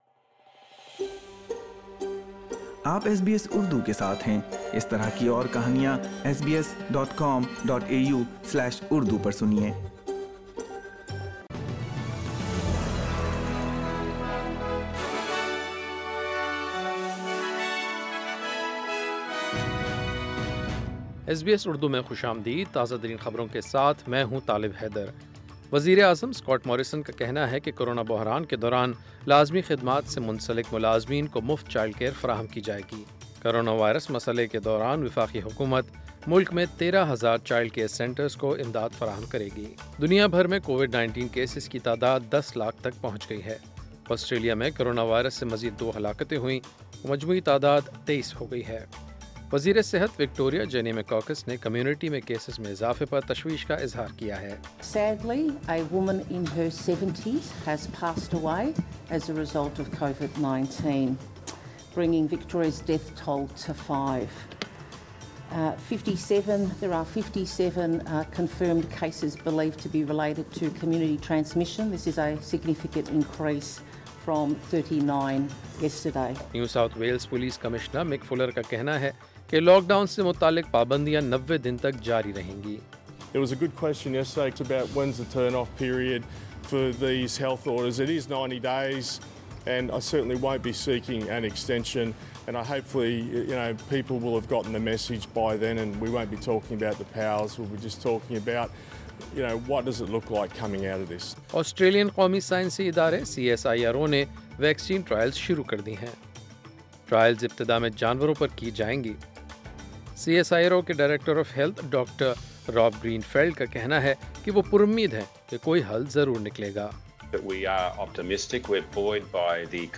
ایس بی ایس اردو خبریں ۲ اپریل ۲۰۲۰